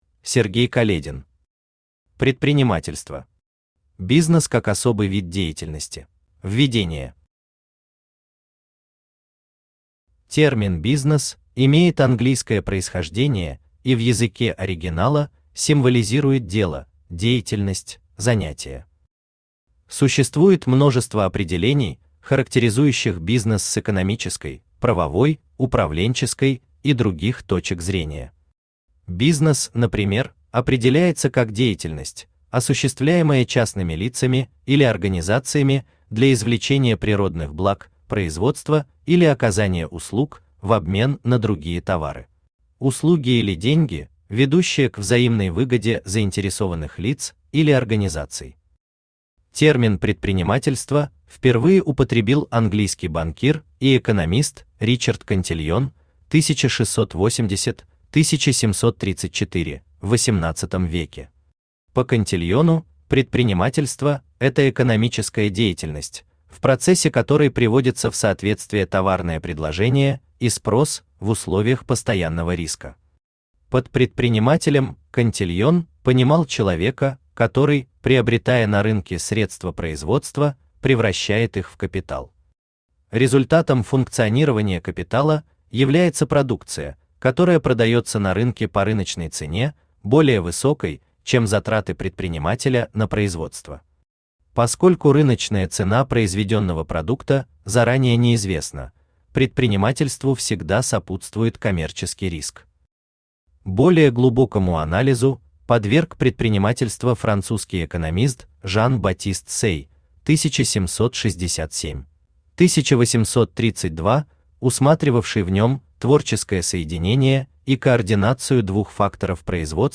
Аудиокнига Предпринимательство. Бизнес как особый вид деятельности | Библиотека аудиокниг
Бизнес как особый вид деятельности Автор Сергей Каледин Читает аудиокнигу Авточтец ЛитРес.